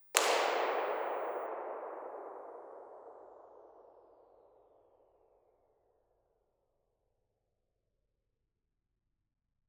Location: Uppsala, Sweden
The entrance hall to the university performance hall is 85 meters long, 20 meters wide and 30 meters high making a volume of at least 50,000 m³.
The average STI is around 0.40 (without noise) which is quite a feat considering the 6 to 7 second reverb time.
File type: B-format, Omni
Microphone: Sennheiser Ambeo™
Source: 14 sec log sweep
Omni-IR-ENTHALL_001.wav